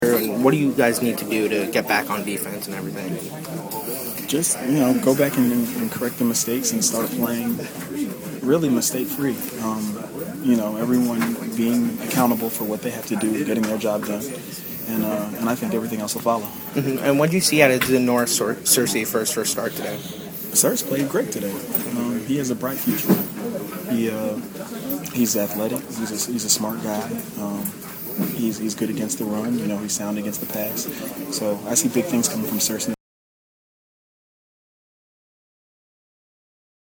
Bryan Scott was the second player I talked to in the Bills’ Locker Room.
bills-bryan-scott-locker-room.mp3